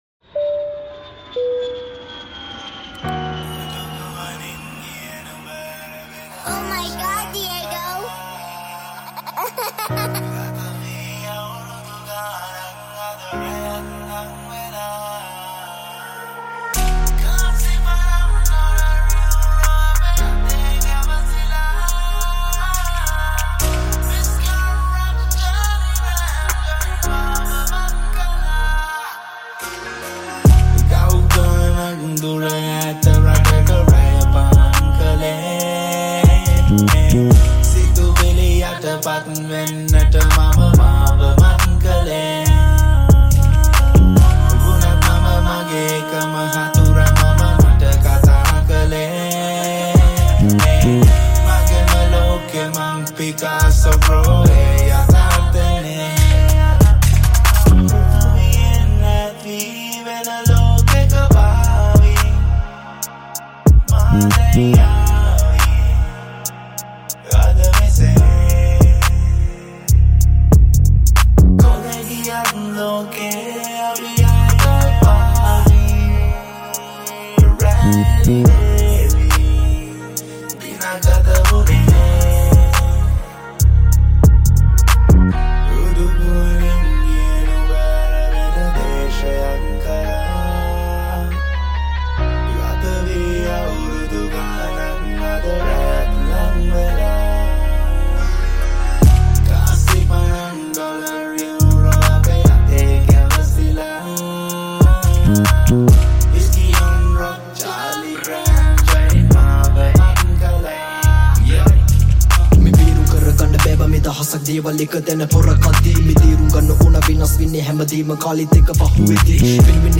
Hiphop